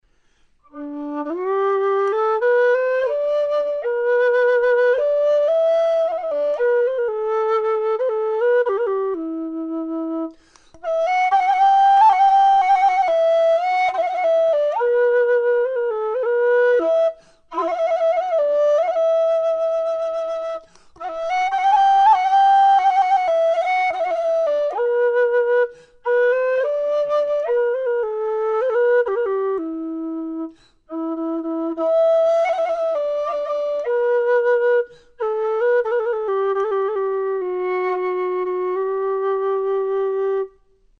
Low D whistle
made out of thin-walled aluminium tubing with 23mm bore